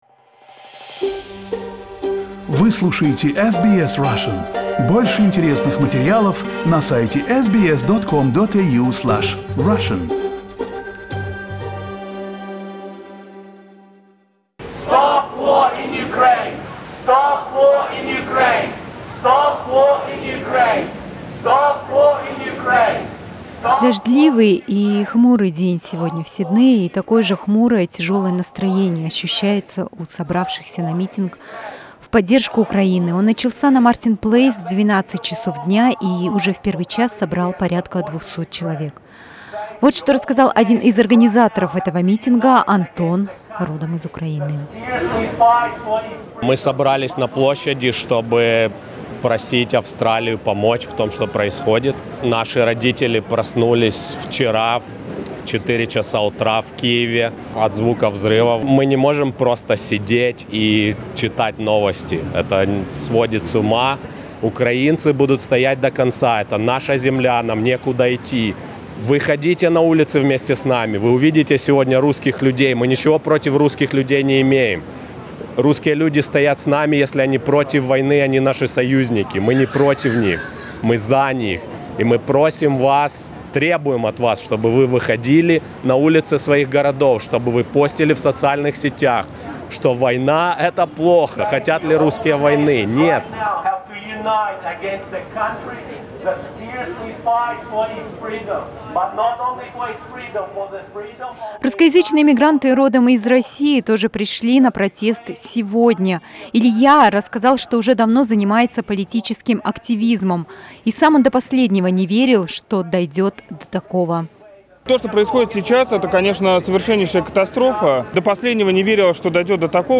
Репортаж с митинга в поддержку Украины, который сегодня проходил в Сиднее.